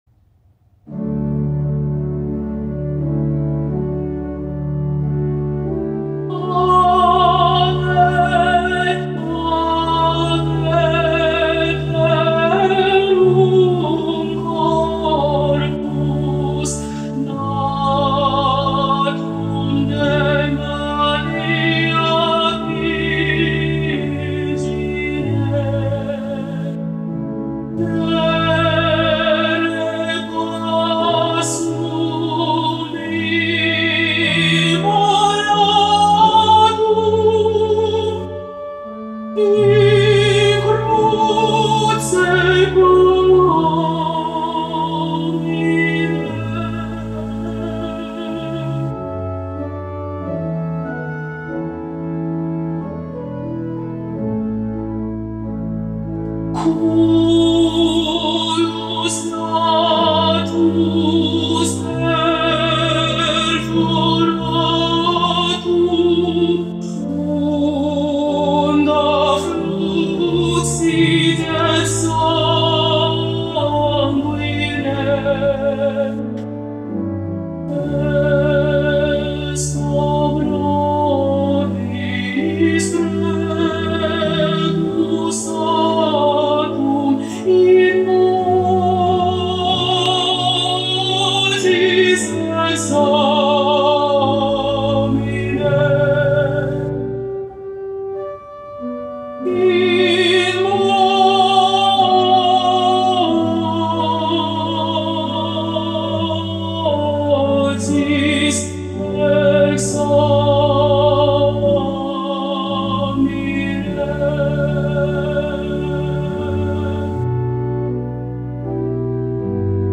MP3 versions chantées
Alto
Ave Verum Corpus Mozart Alto Practice Mp 3